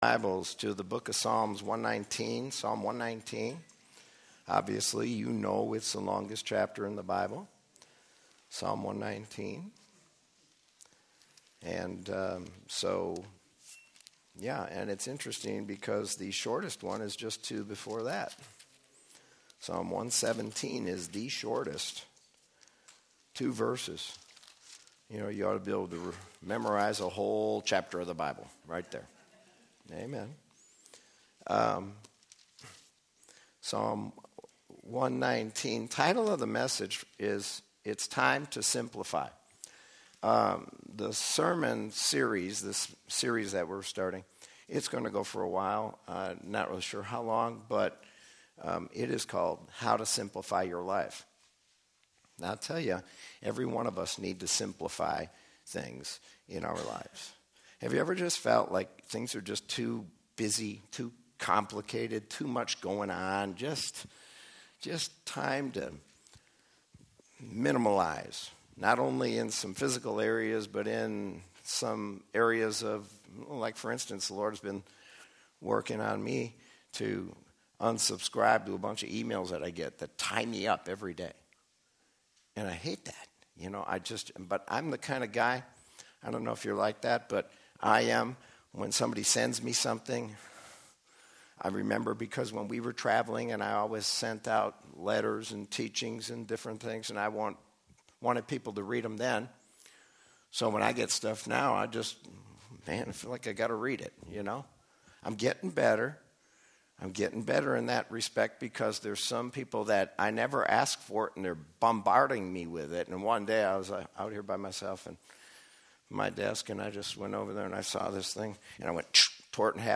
Sermon from January 5, 2020.